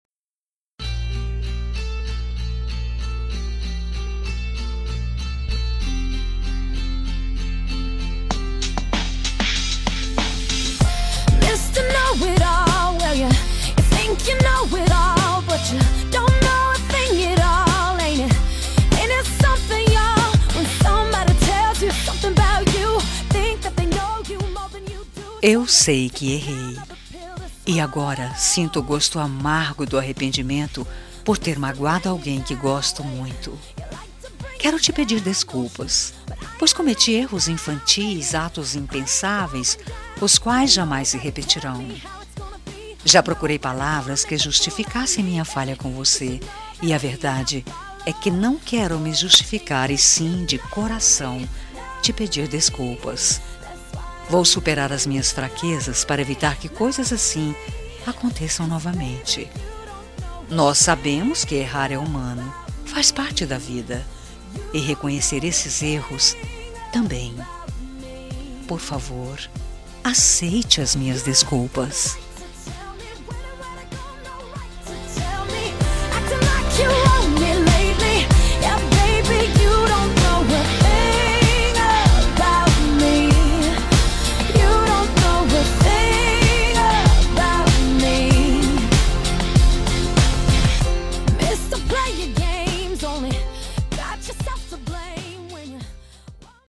Telemensagem de Desculpas – Voz Feminina – Cód: 202035